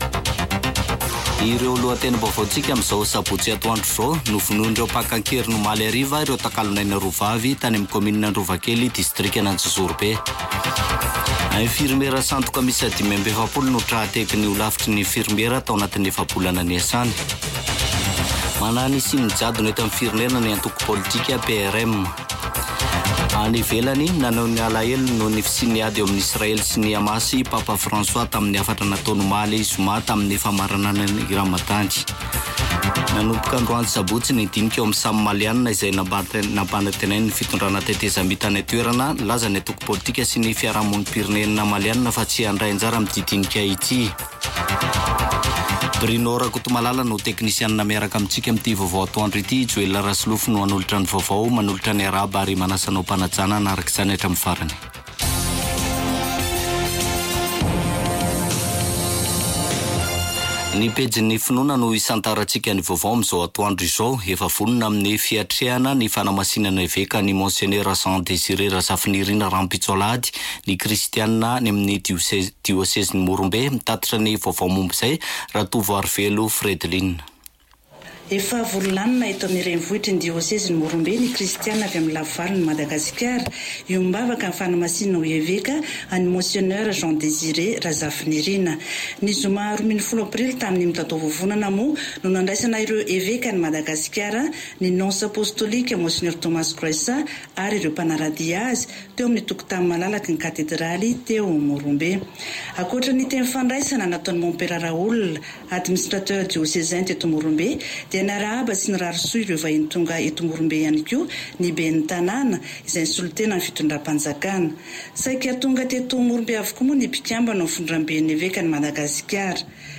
[Vaovao antoandro] Sabotsy 13 aprily 2024